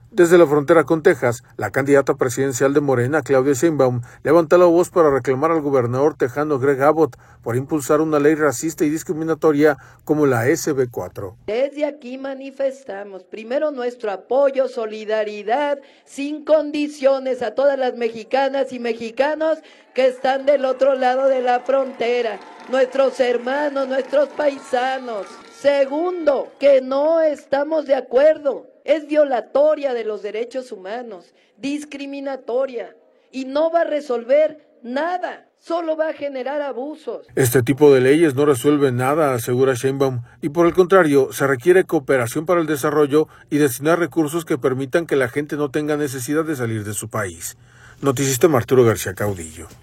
Desde la frontera con Texas, la candidata presidencial de Morena, Claudia Sheinbaum, levantó la voz para reclamar al gobernador texano Greg Abott, por impulsar una ley racista y discriminatoria como la SB4.